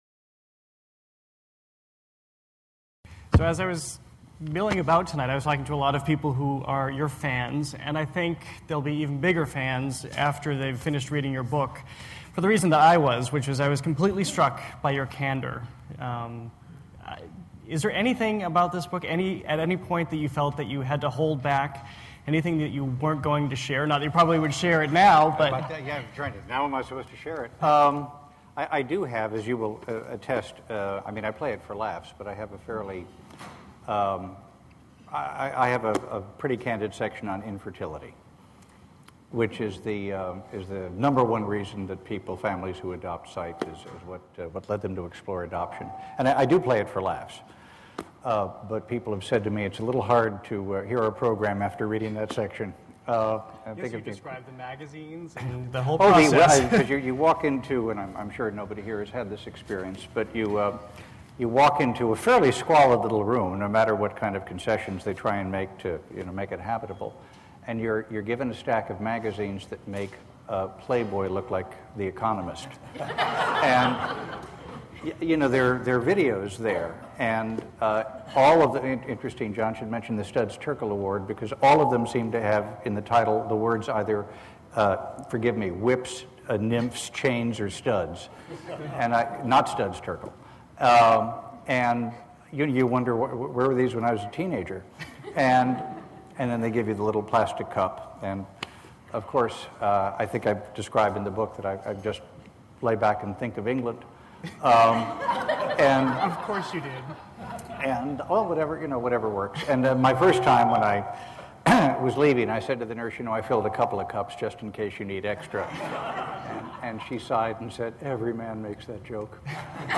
Scott Simon, NPR's award-winning host of Weekend Edition, speaks about his book Baby, We Were Meant for Each Other: In Praise of Adoption, which was inspired by his two daughters.